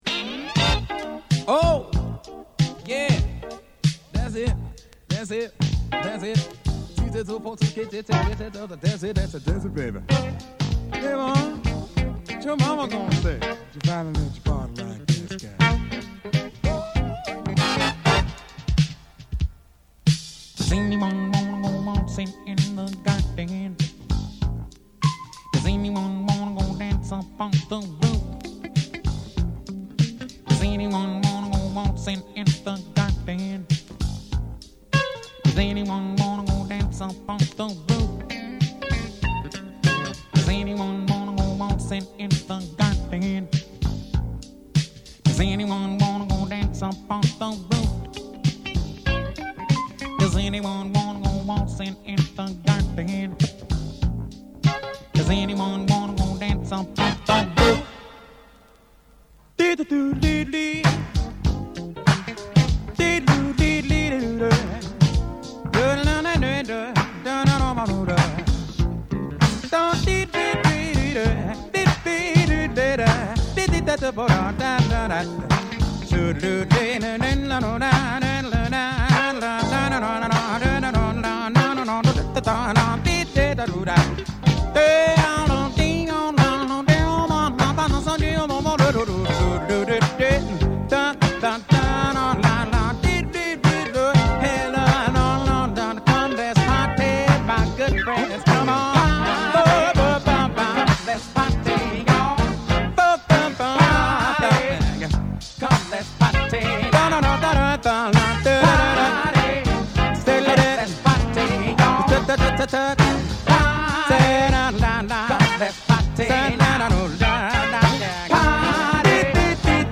Ora anche il Funky